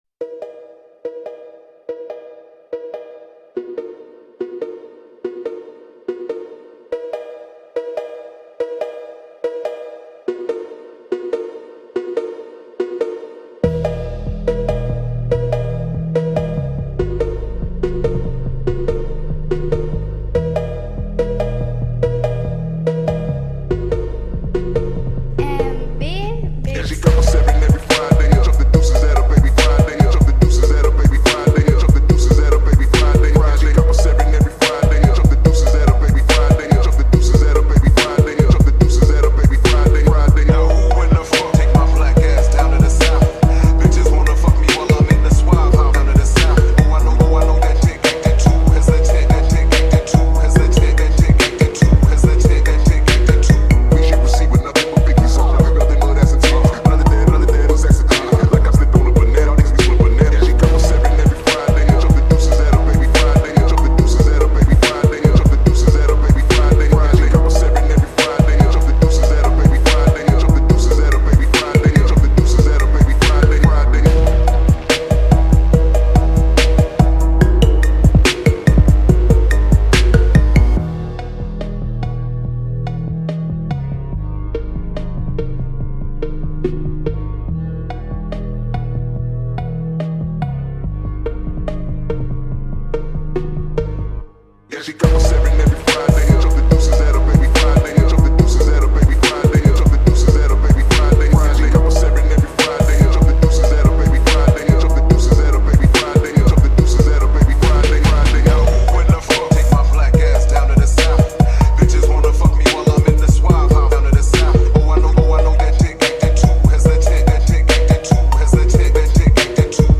very old phonk beat I randomly found on my computer
phonk hip hop rap beat chill instrumental trap